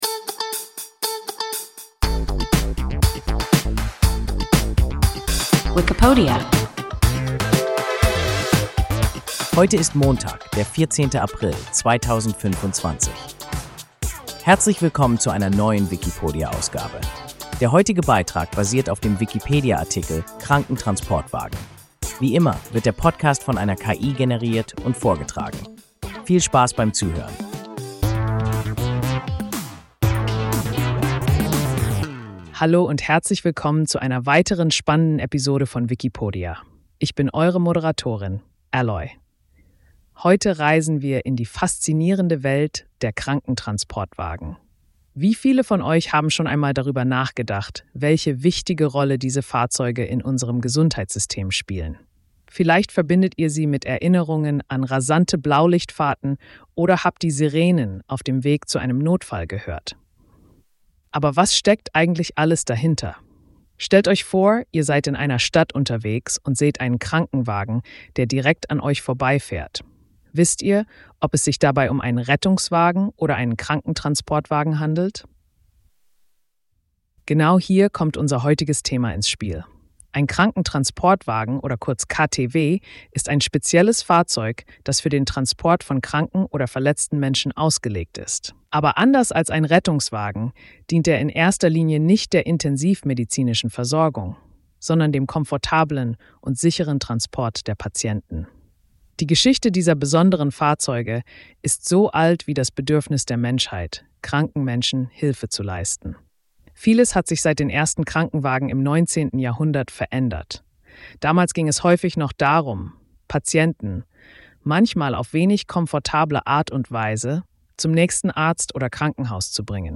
Krankentransportwagen – WIKIPODIA – ein KI Podcast